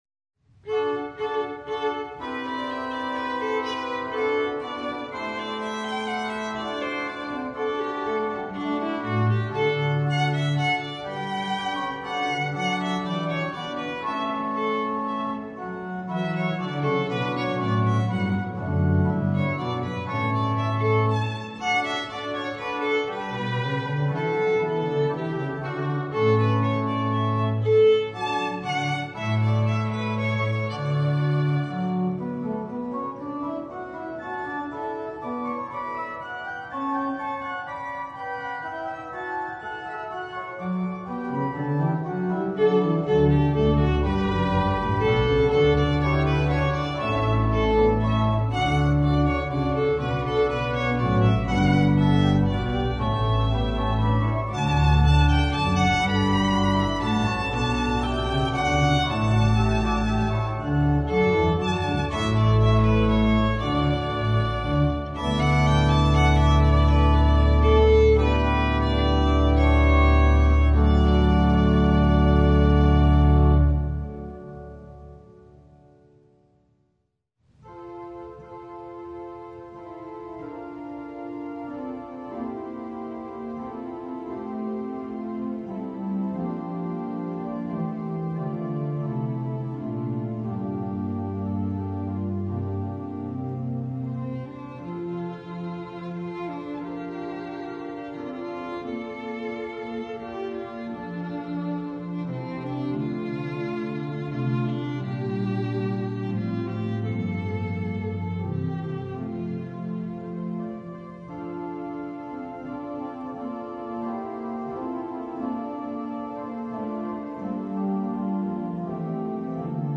Voicing: Instrument and Organ